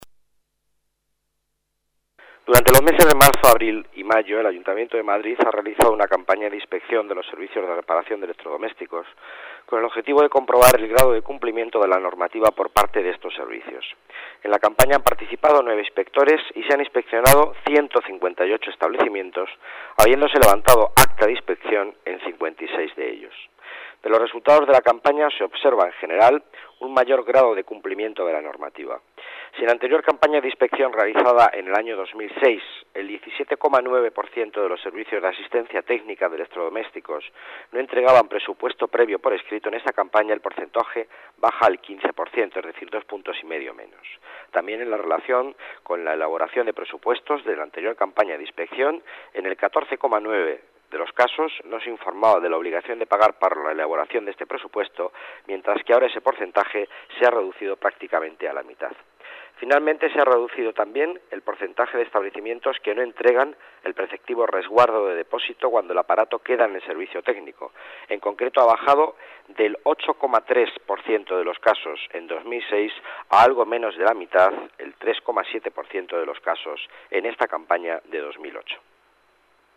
Nueva ventana:Declaraciones del delegado de Economía y Empleo, Miguel Ángel Villanueva